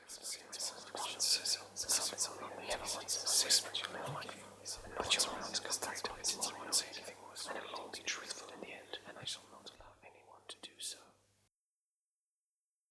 whispering.wav